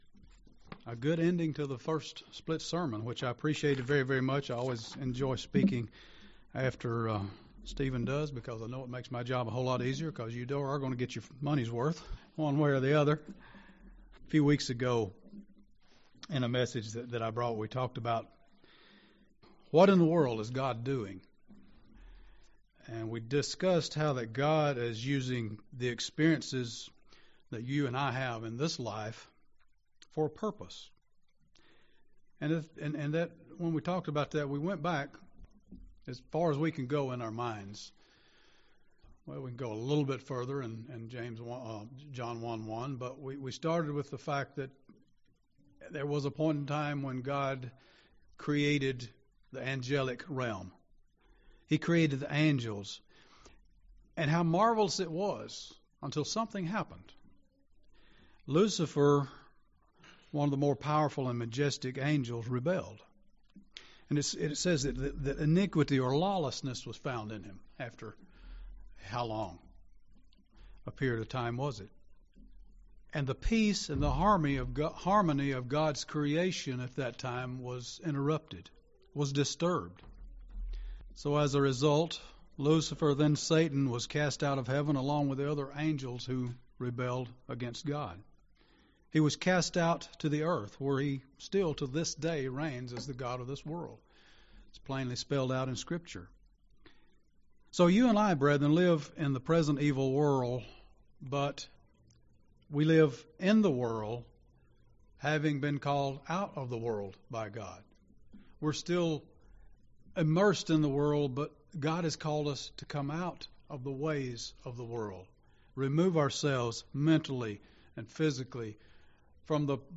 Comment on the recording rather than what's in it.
Given in Gadsden, AL